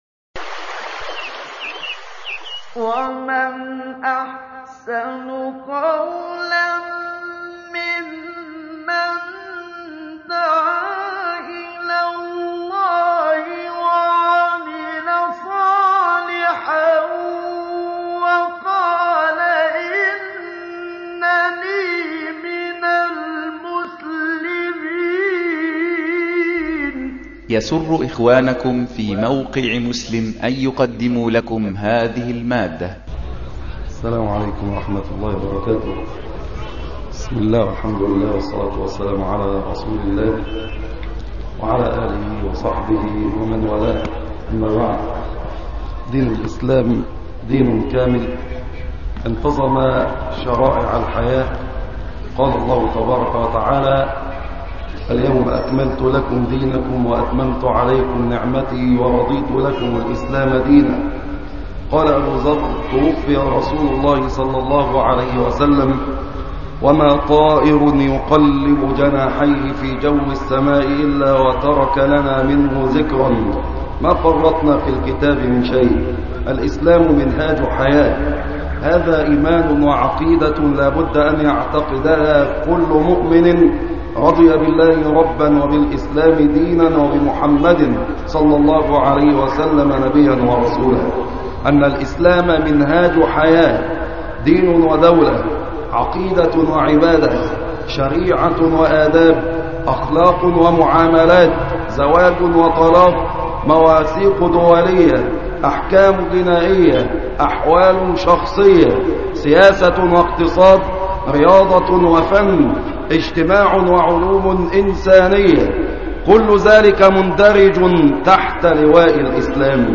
الخطب والمحاضرات